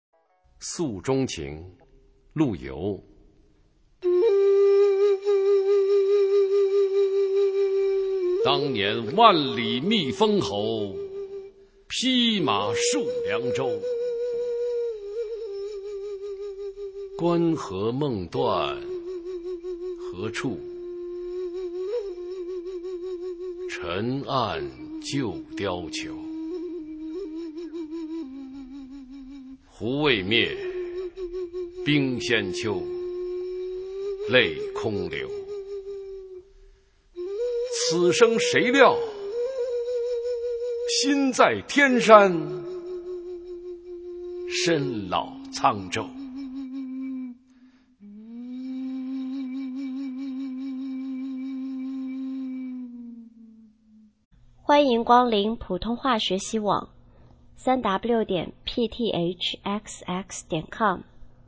普通话美声欣赏：诉衷情